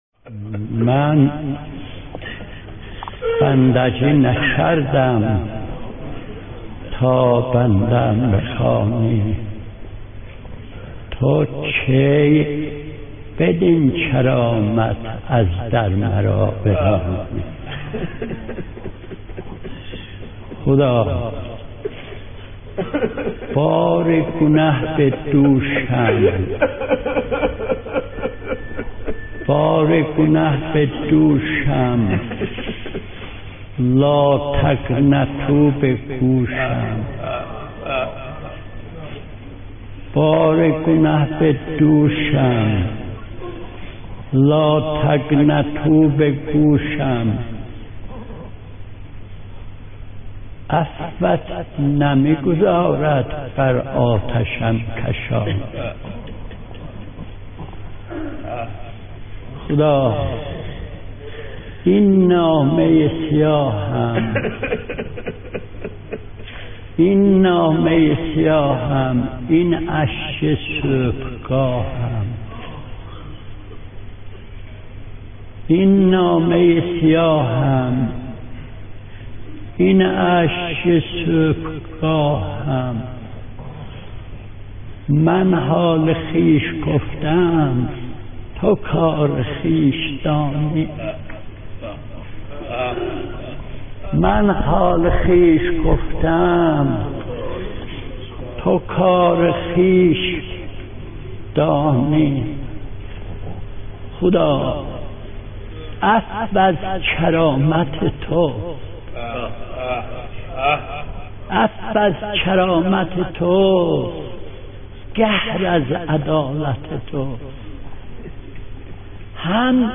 مناجات با خدا و روضه عرشی